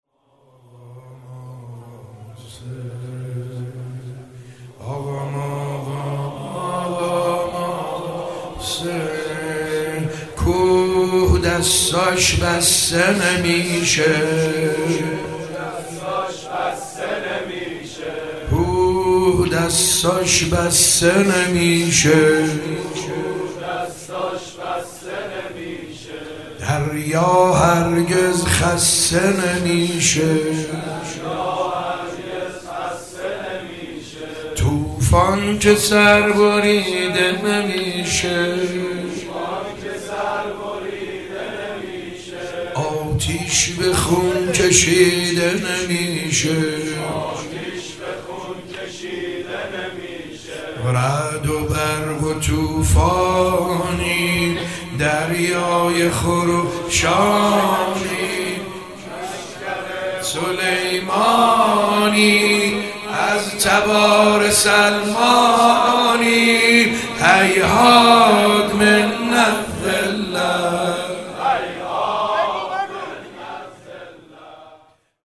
شعر حماسی